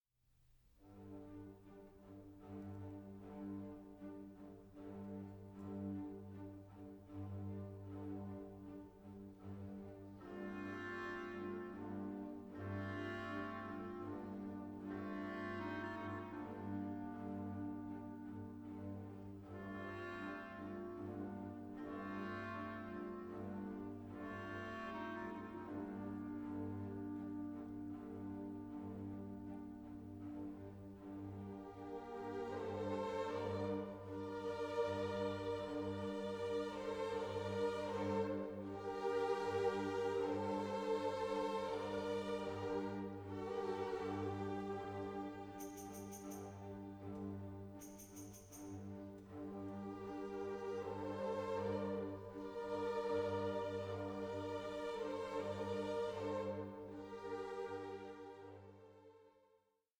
(DSD DSF) Stereo & Surround  20,99 Select